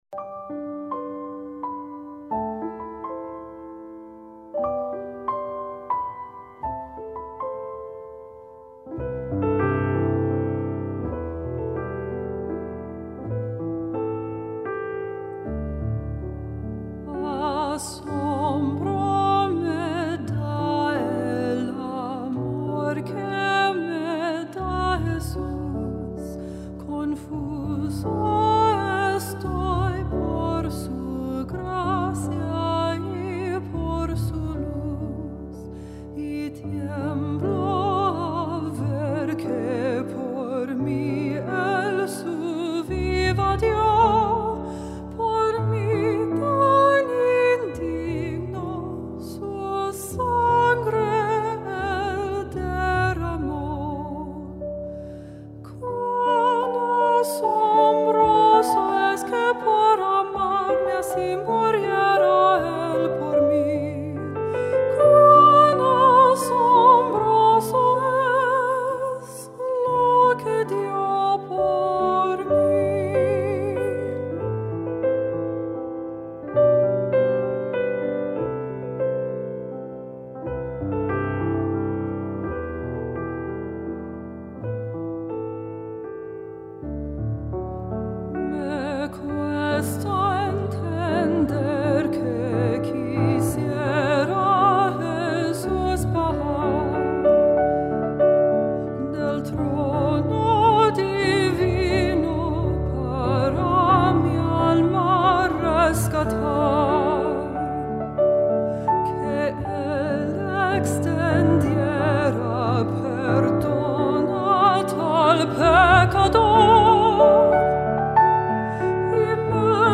Alto and Piano
Hymn arrangement.